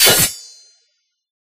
Sword6.ogg